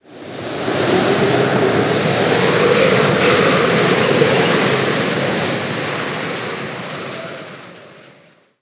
wind2.wav